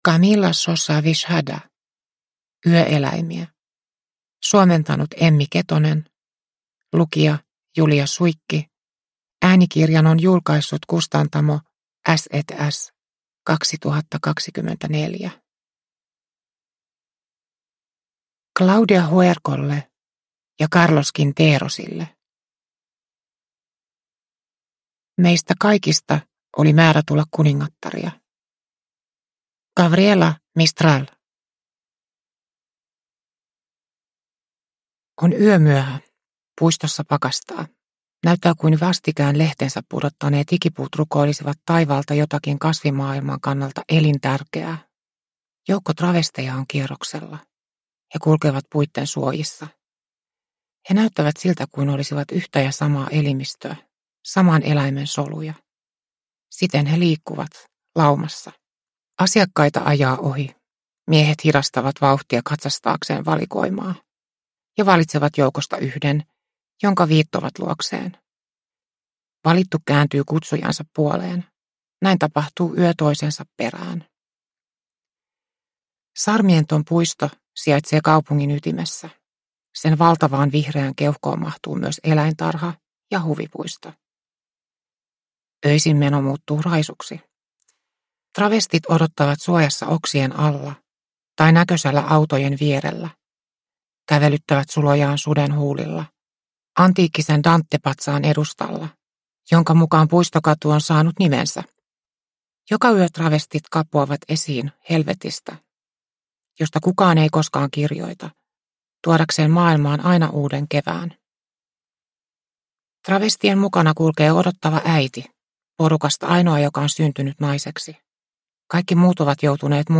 Yöeläimiä – Ljudbok